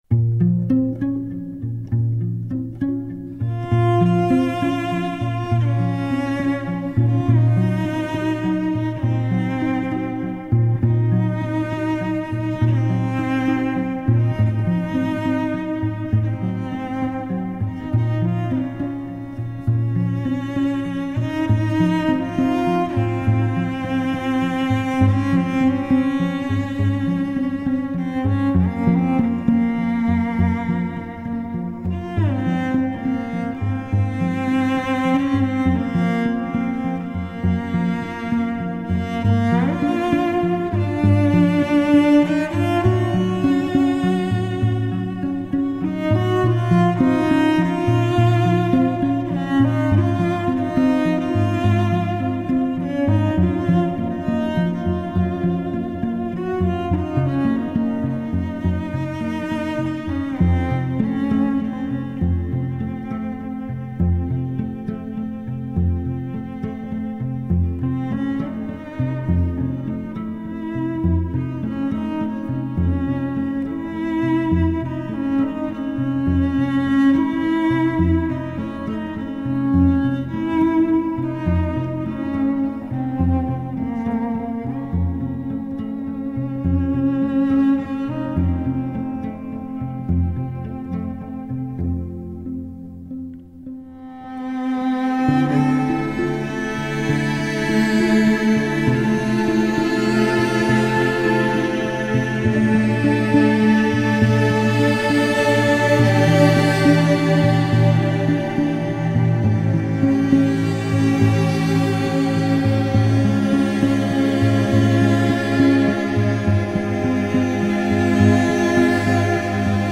playing cello in a modern style.